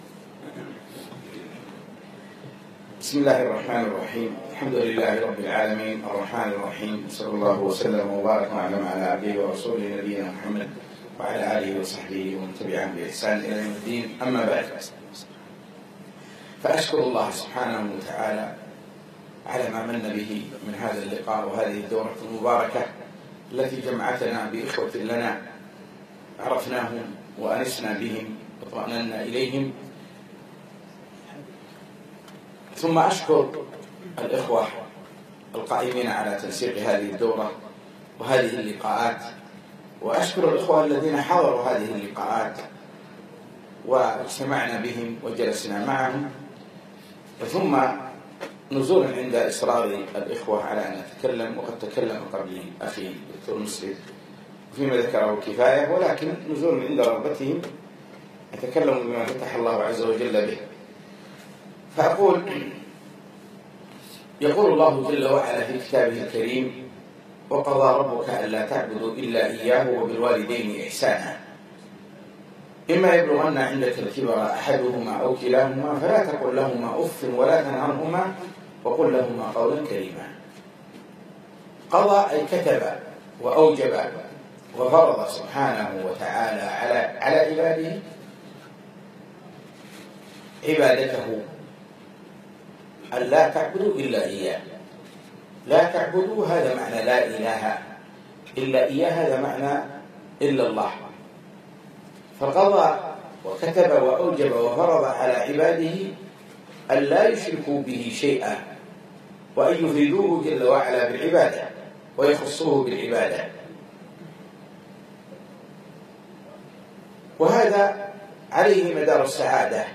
بر الوالدين - كلمة في استراحة مشروع الهداية